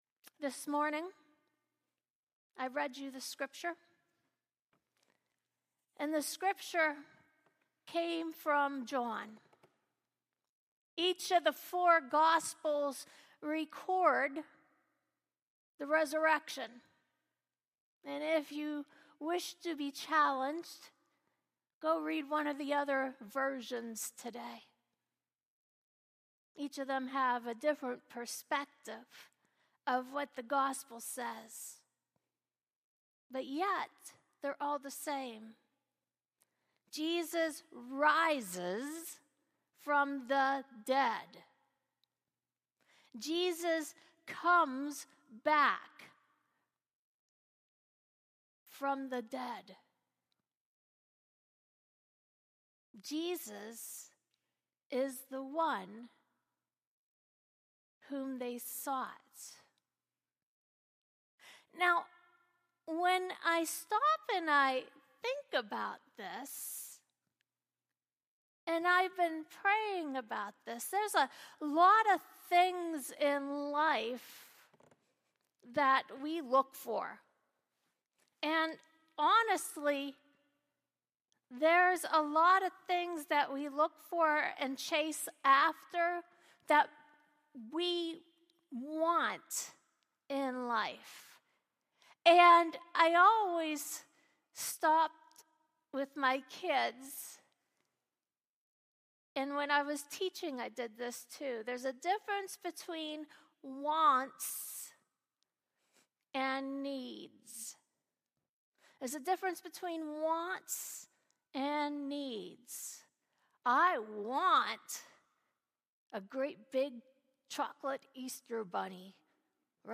Sermons | Stone UMC